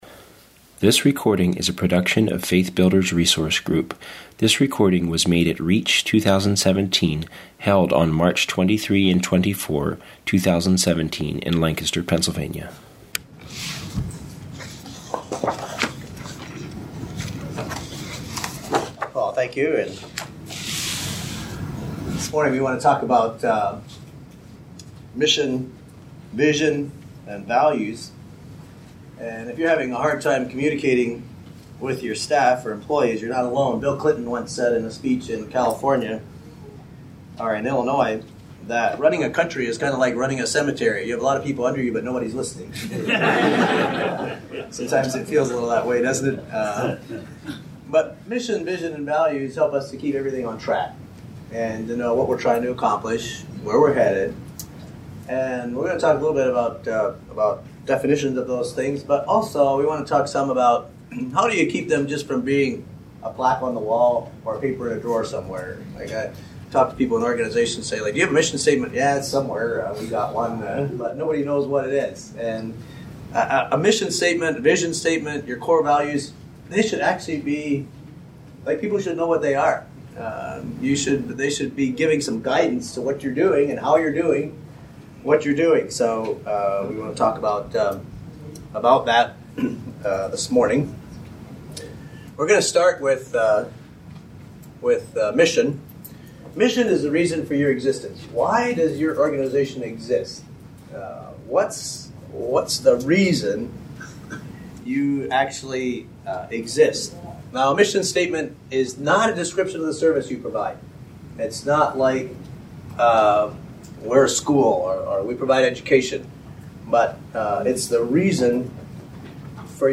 Home » Lectures » Clarifying Mission Vision and Values